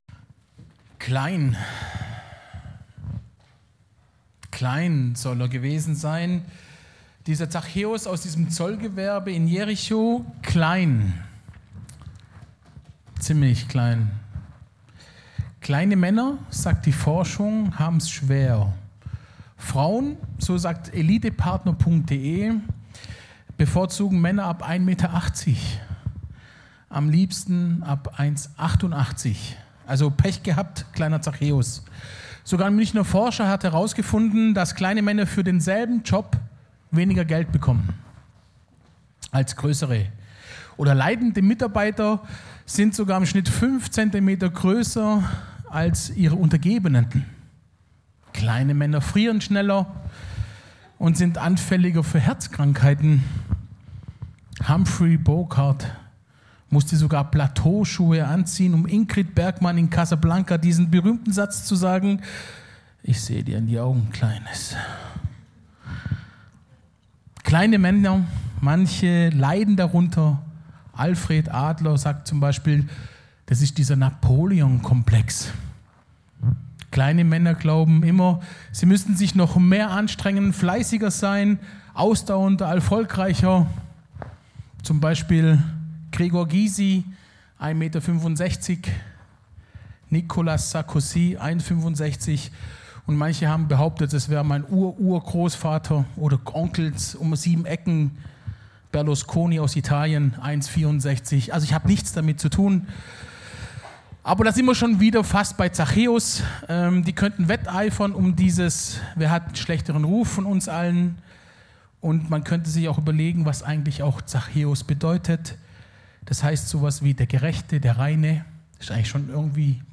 Predigt vom 09. Juni 2024 – Nachmittag – Süddeutsche Gemeinschaft Künzelsau